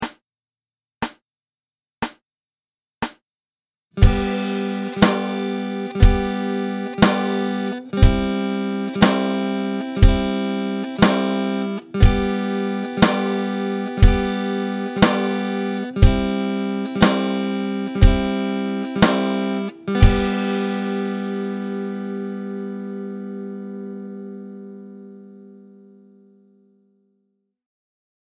Example 3: One-Finger G and One-Finger C Chord, Open-String Guitar Chords Exercise
As you fret that note, strum the top 3 strings. Use down strums and strum each chord 4 times.
Ex-3-One-Finger-G-and-C-Chord-Exercise.mp3